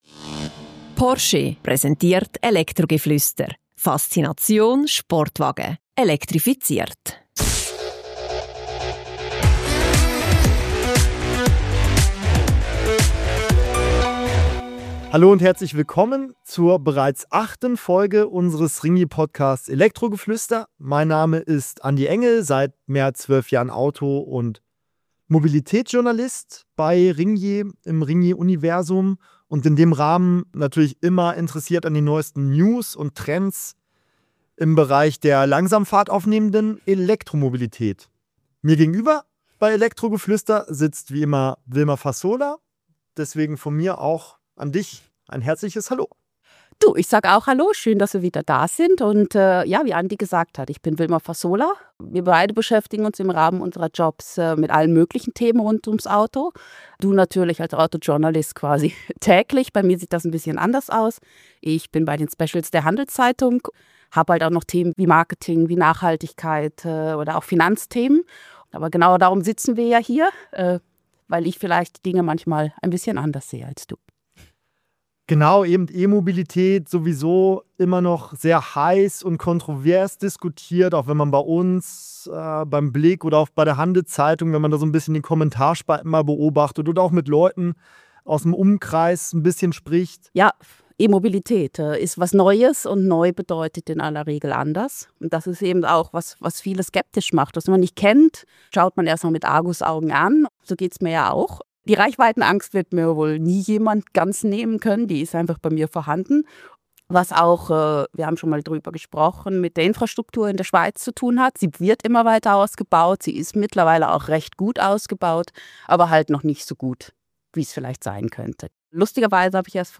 Alle zwei Wochen diskutieren die Hosts